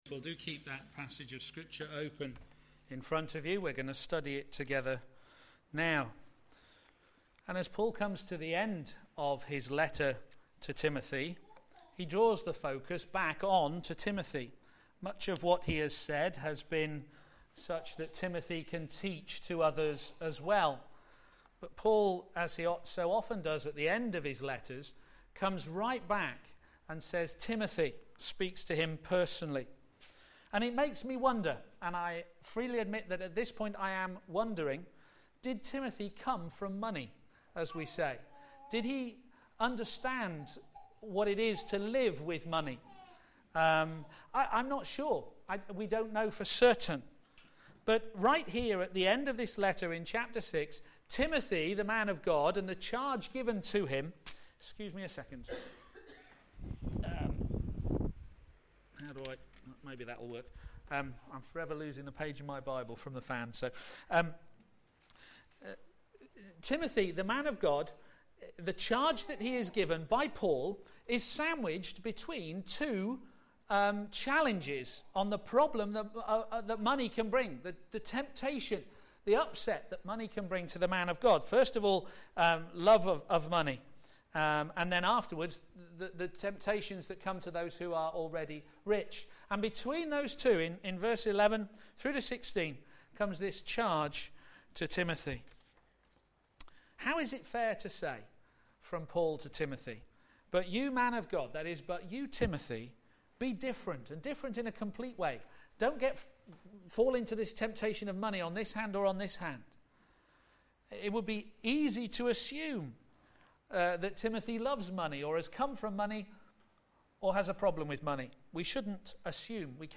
a.m. Service
Series: The Church and its Ministry Theme: Final words to the minister Sermon